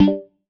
Simple Cute Alert 31.wav